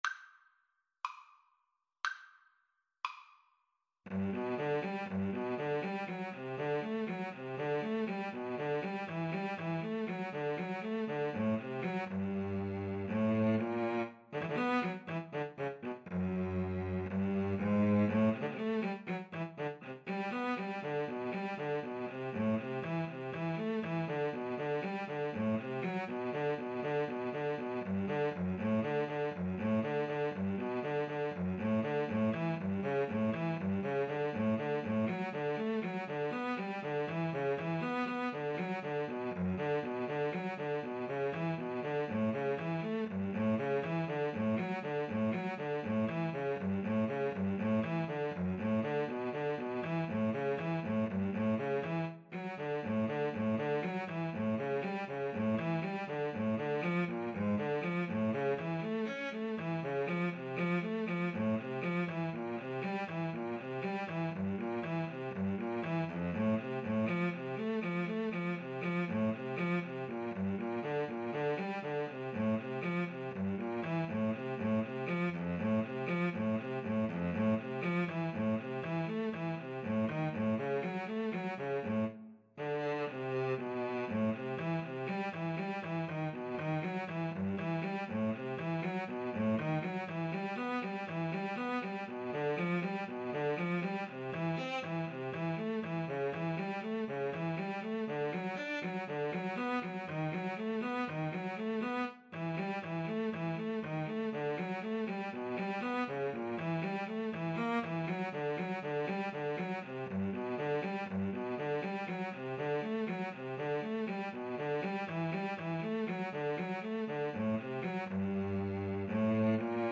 adagio Slow =c.60
Classical (View more Classical Violin-Cello Duet Music)